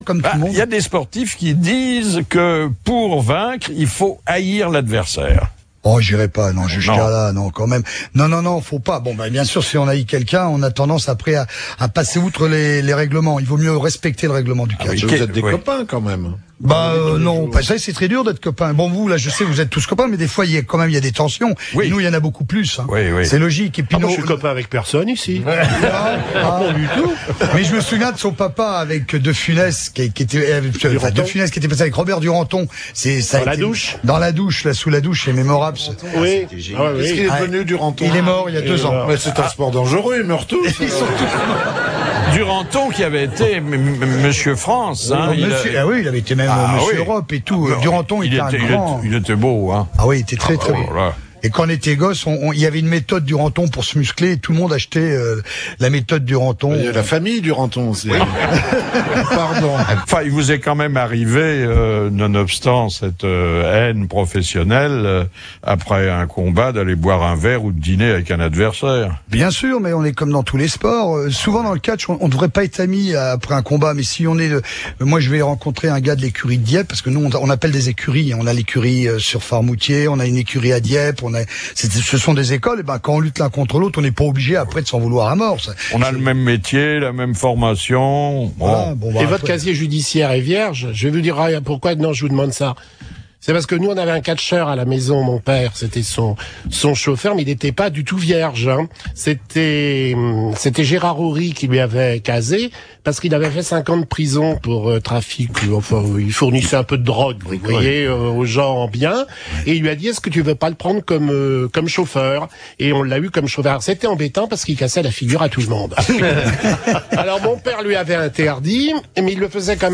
Ci dessous un extrait de l'émission du 18 Février 2010